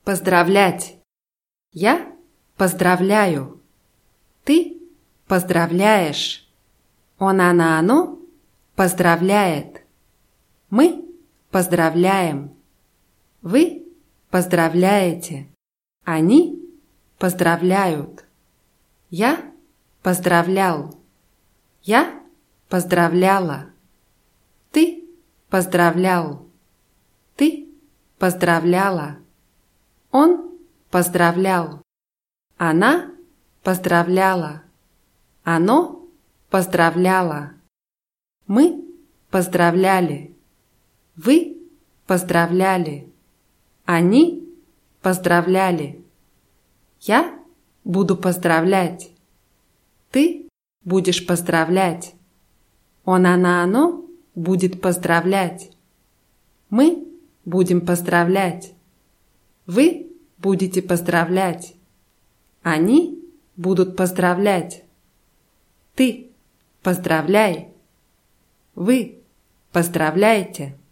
поздравлять [pazdrawlʲátʲ]